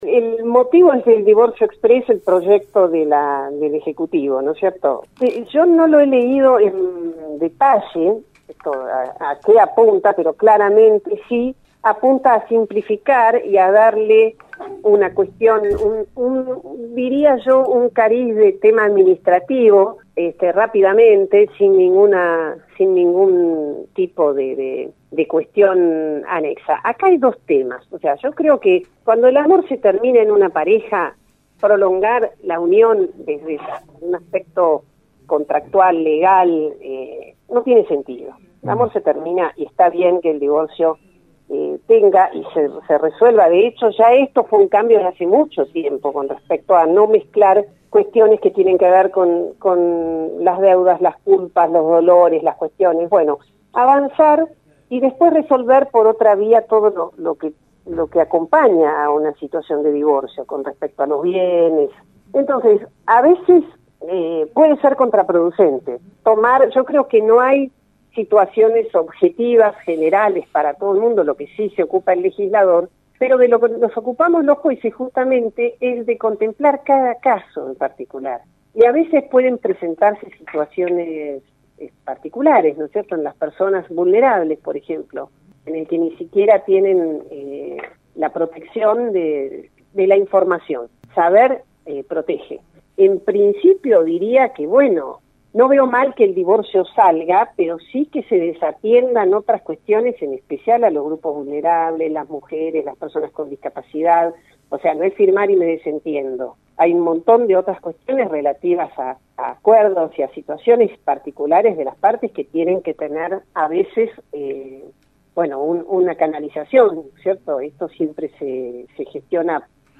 En la mañana del jueves en el programa Encuentro que se emite por Radio Universo de lunes a viernes de 8 a 12 hs tuvimos la oportunidad de dialogar con la jueza en lo civil y comercial Dra Soledad de Vedia que pertenece al departamento judicial de San Isidro sobre el tema divorcios y sucesiones exprés.